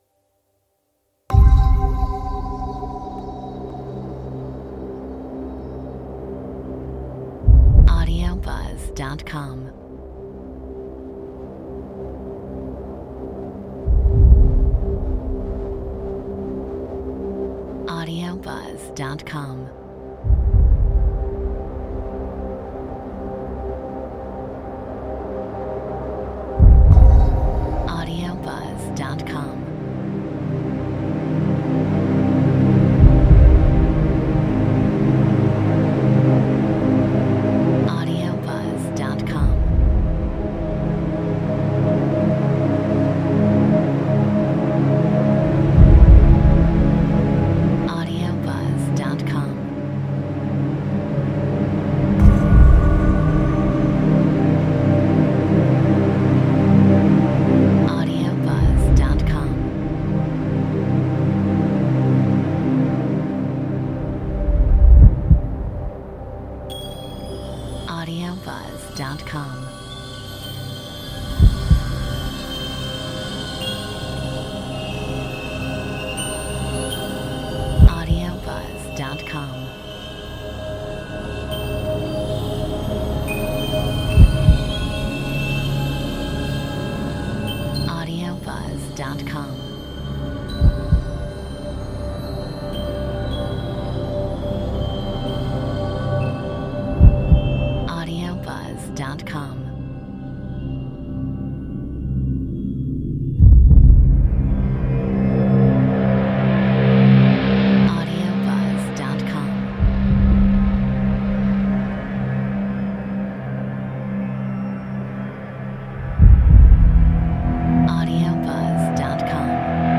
Metronome 75